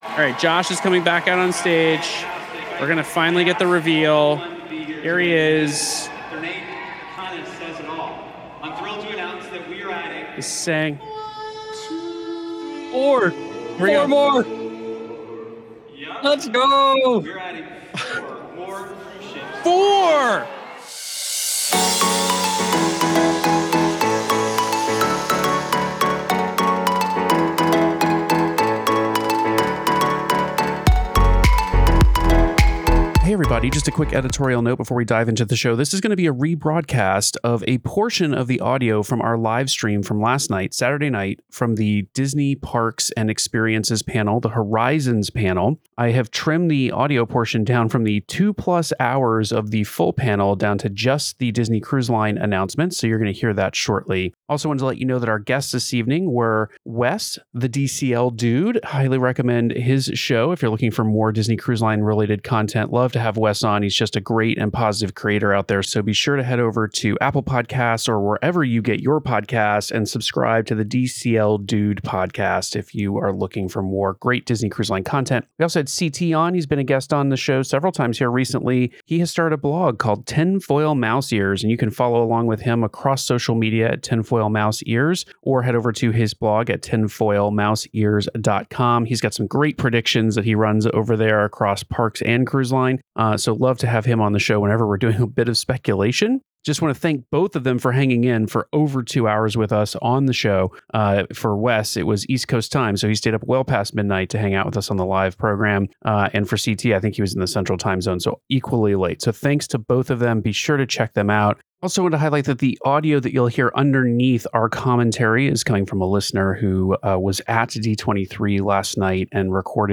Ep. 450 - Live Bonus Show - All Four One: Live Reactions to Disney Cruise Line's D23 Announcements